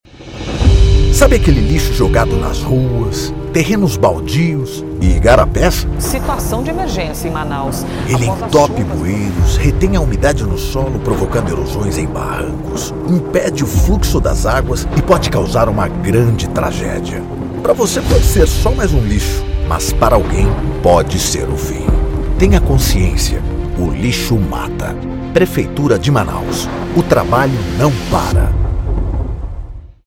SPOT-O-LIXO-MATA.mp3